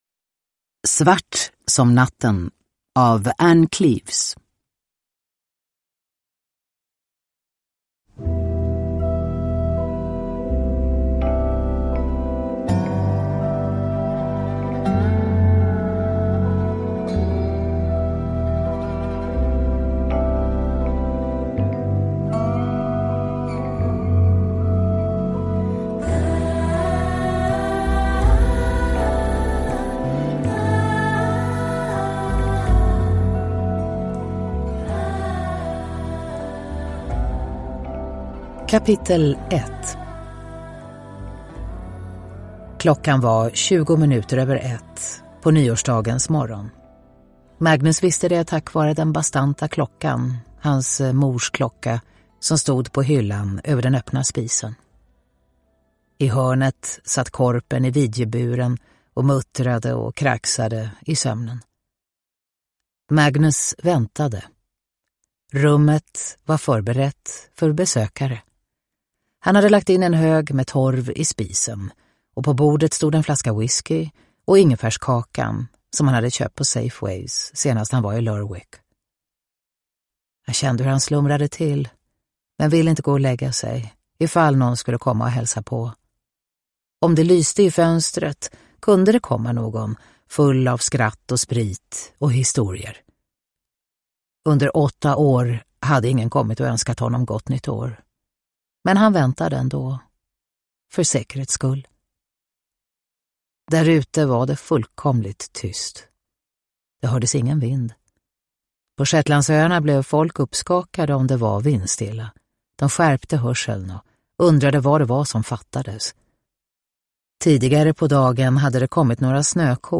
Svart som natten – Ljudbok – Laddas ner
Uppläsare: Katarina Ewerlöf